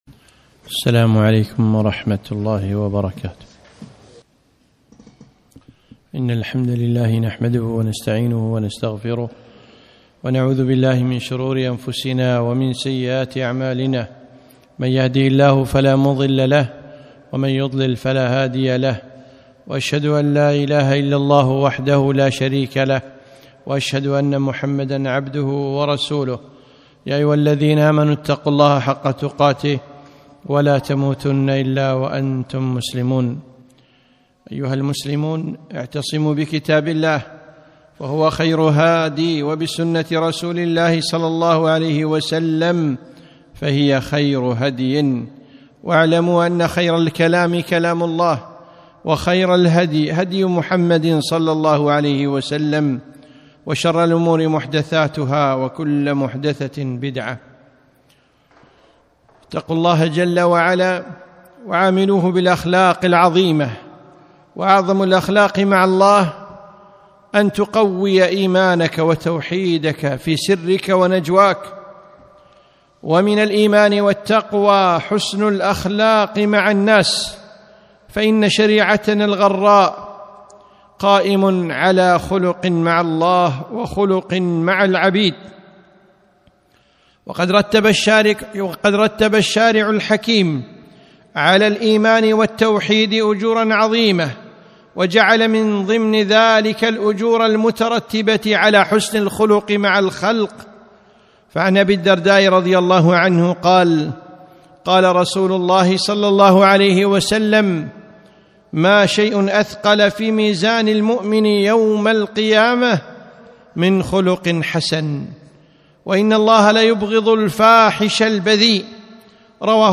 خطبة - حسن الخلق